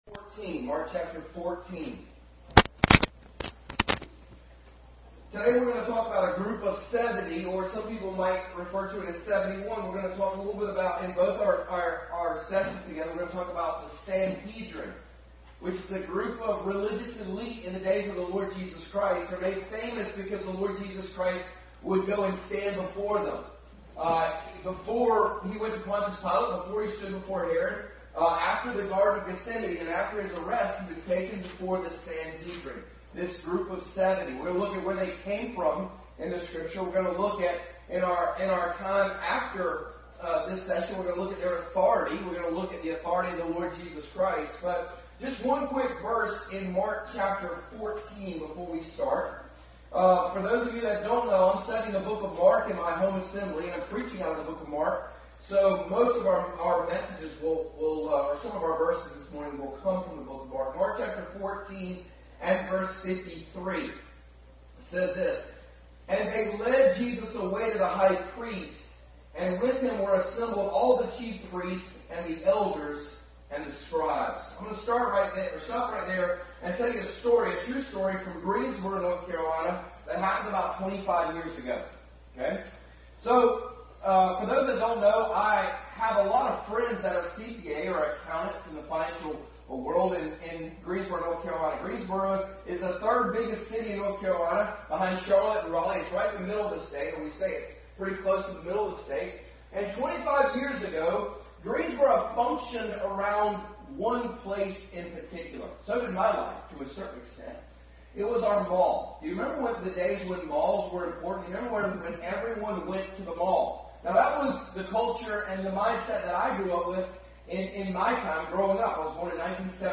Passage: Mark 14:53-65 Service Type: Sunday School